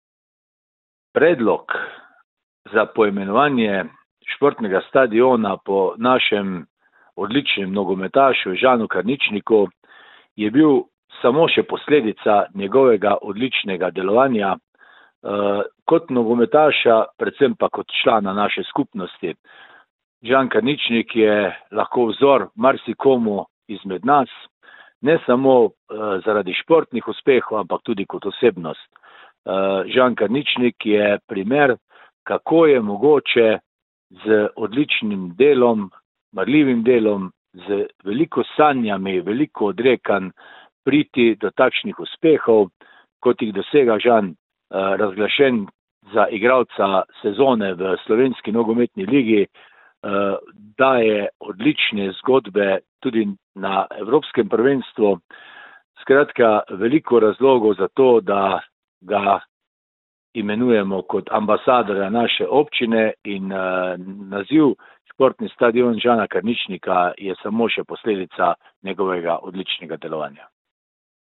Radeljski občinski svet je soglasno odločil: njihov stadion, ki še nima uradnega imena, bodo poimenovali po domačinu Žanu Karničniku. Odločitev je pojasnil radeljski župan Alan Bukovnik:
izjava Bukovnik - stadion za splet .mp3